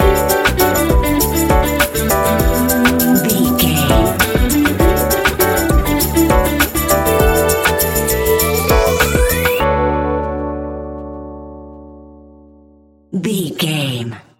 Ionian/Major
D♭
chilled
laid back
Lounge
sparse
new age
chilled electronica
ambient
atmospheric
instrumentals